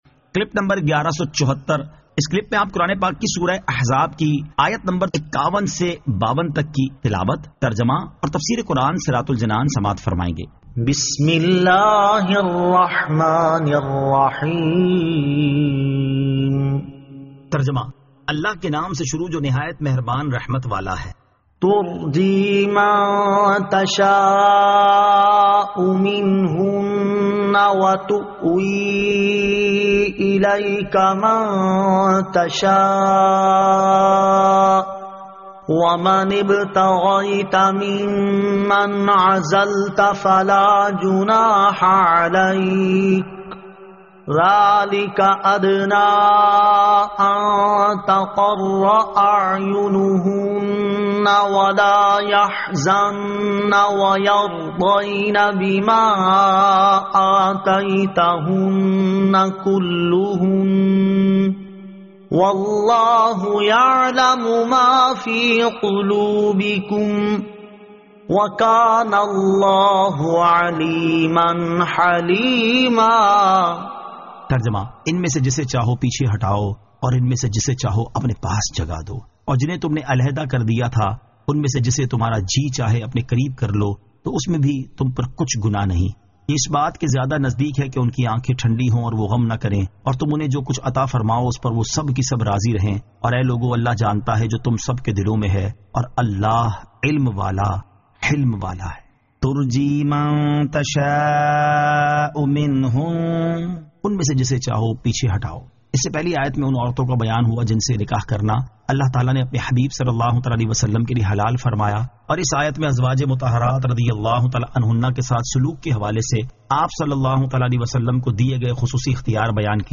Surah Al-Ahzab 51 To 52 Tilawat , Tarjama , Tafseer